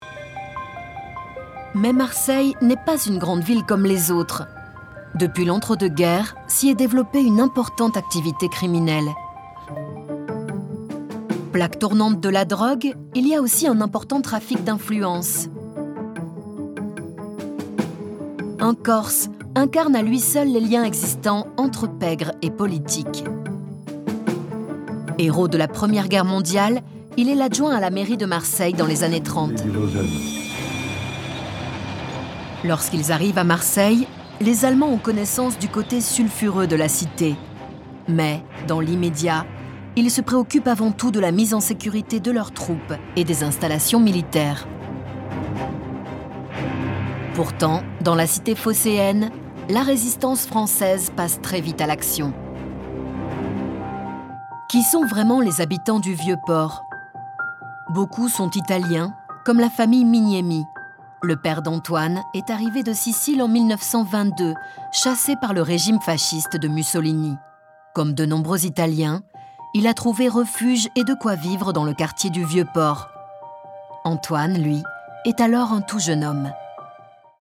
Female
Bright, Friendly, Versatile
I embody the voice of an active, friendly, and naturally engaging young woman.
Microphone: TLM103